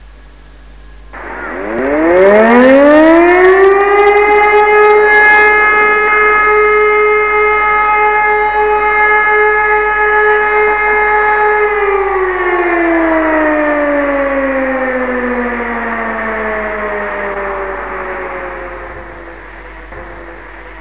Sirenenprobe
Jeden Samstag mittags, Dauerton von 15 Sekunden, dient nur der Funktionsüberprüfung
sirenenprobe.wav